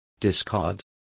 Complete with pronunciation of the translation of discard.